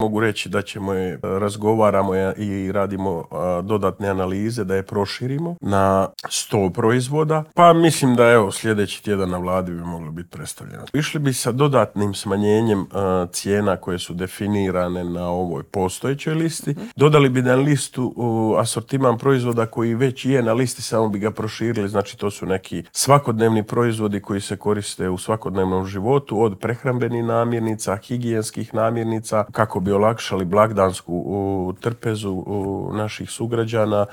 ZAGREB - Vlada će proširiti popis namirnica kojima će zamrznuti cijene, najavio je ministar gospodarstva Ante Šušnjar gostujući u Intervjuu tjedna Media servisa.